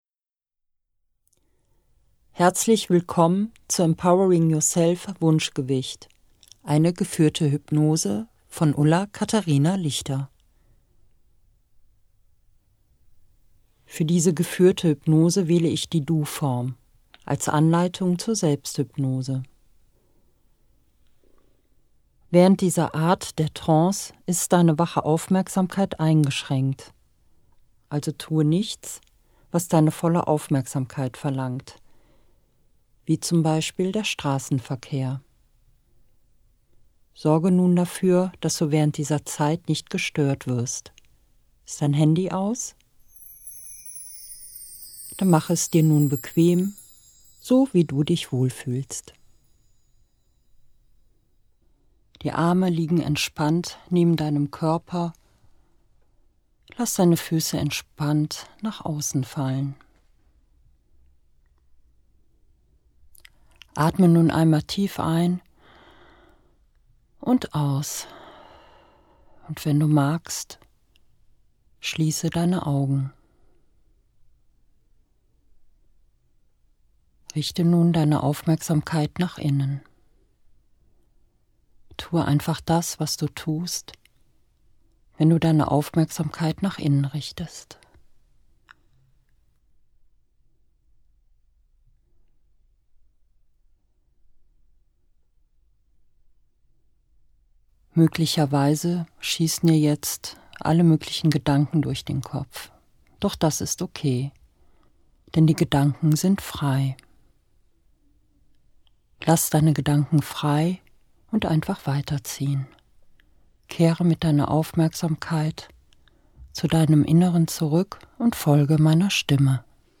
Die Hypnose gibt es in zwei Ausführungen: Einmal mit EMDR-Musik unterlegt, einmal ohne.
"Ernährung umstellen" ohne EMDR-Musik